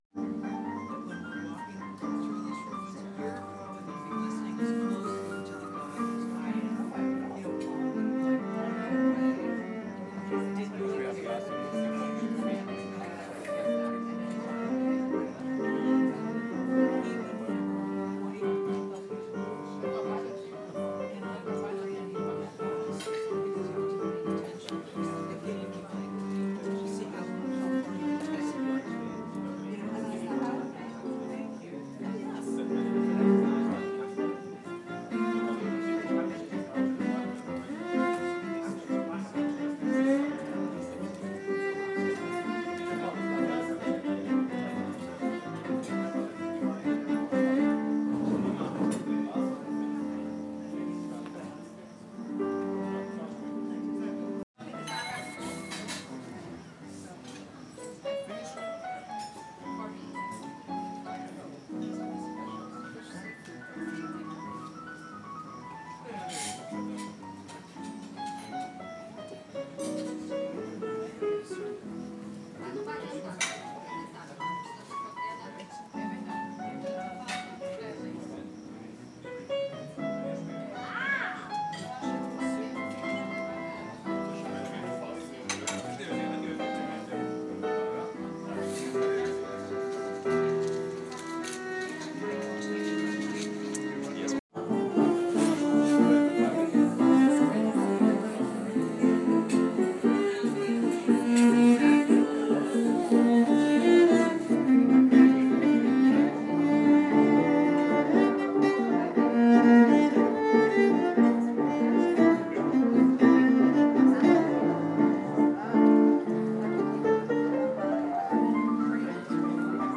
Cello piano Duo Dubrovnik